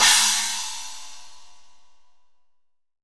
20  CHINA2.wav